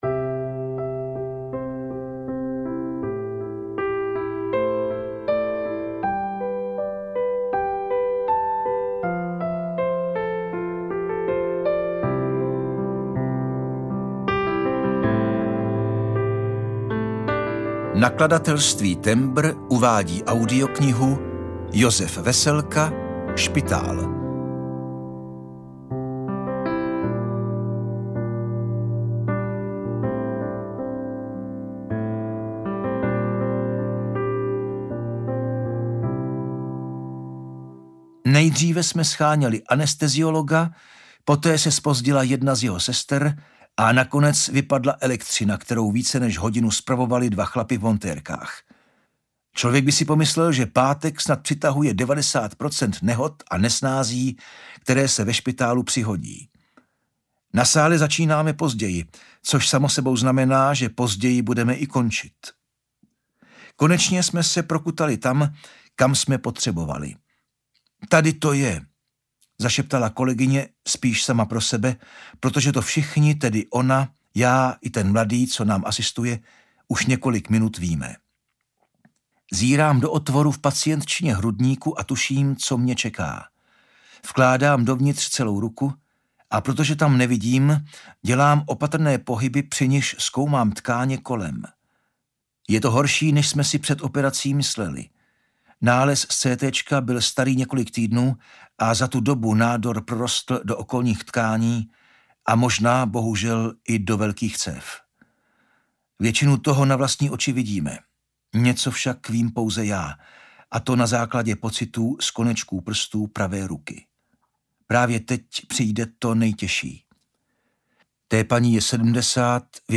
Špitál audiokniha
Ukázka z knihy